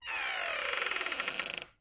Creak
Door Open Close / Squeaks, Various; Door Squeaks 2